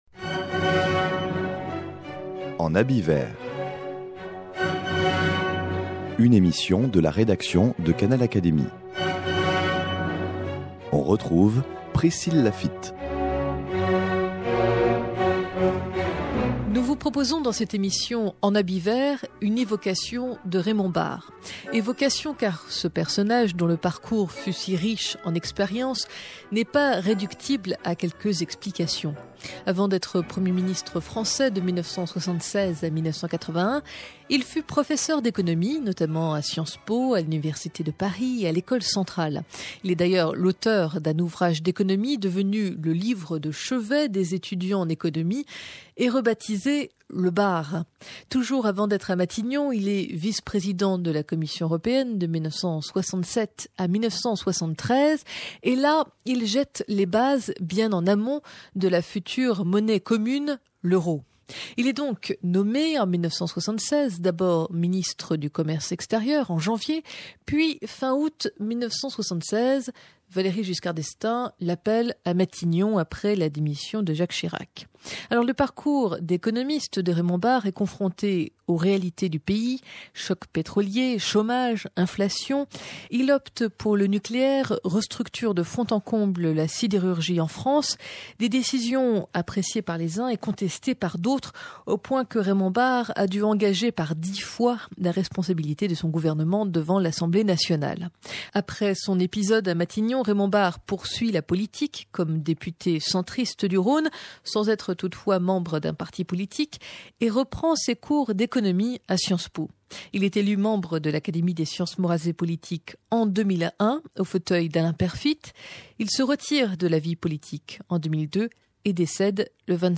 Raymond Barre : témoignage de Jacques de Larosière
Dans ce court témoignage, Jacques de Larosière évoque Raymond Barre, à l'époque où il était Premier ministre.